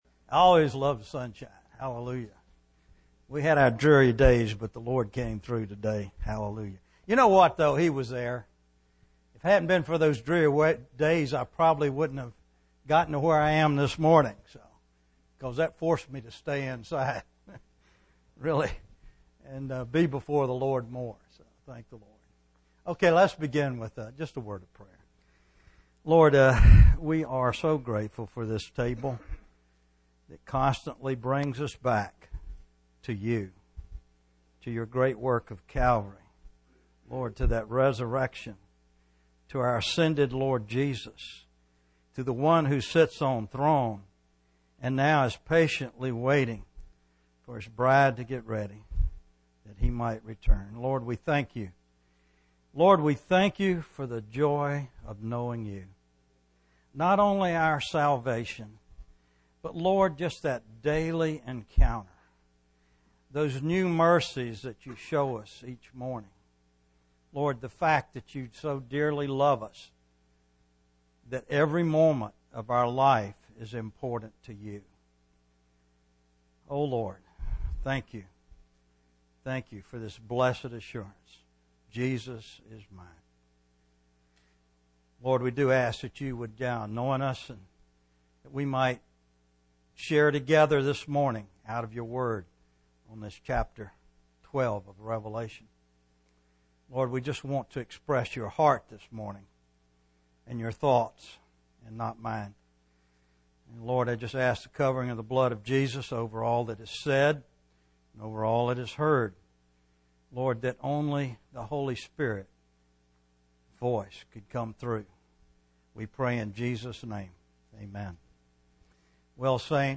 A collection of Christ focused messages published by the Christian Testimony Ministry in Richmond, VA.
Richmond, Virginia, US